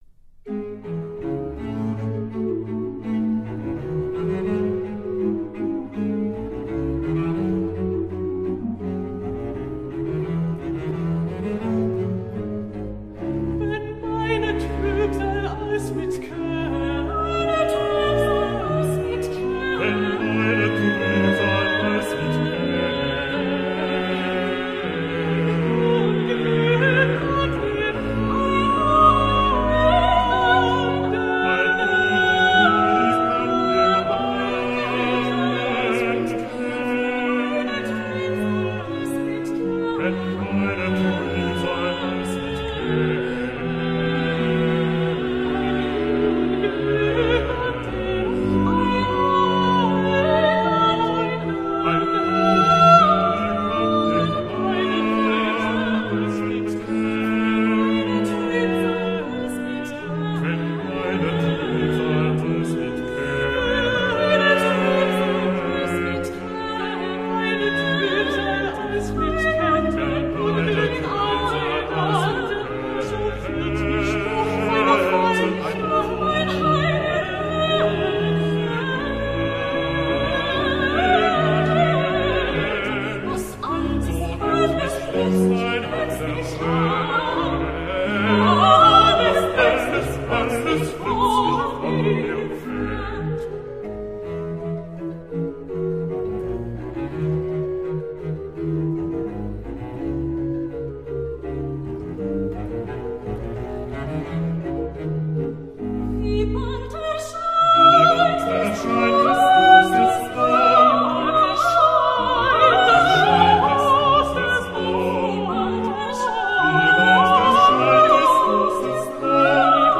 BACH, cantate Bwv 38
trio sop alto bass